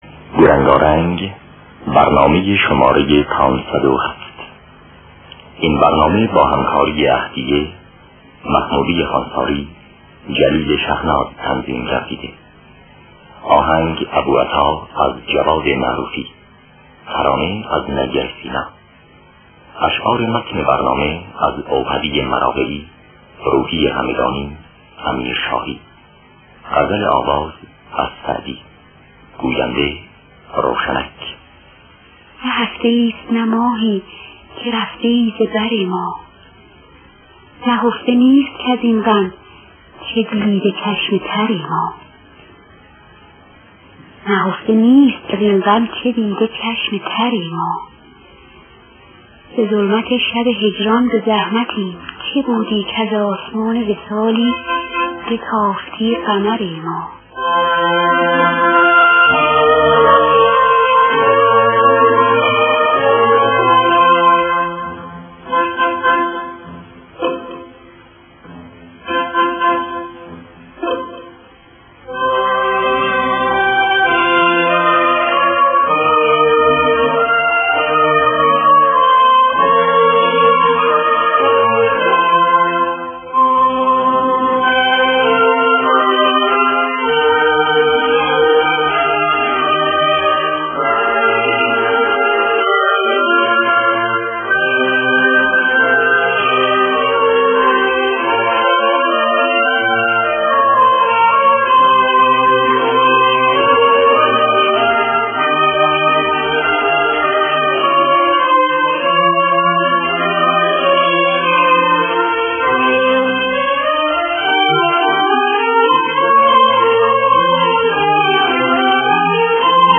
دانلود گلهای رنگارنگ ۵۰۷ با صدای عهدیه، محمودی خوانساری در دستگاه ابوعطا.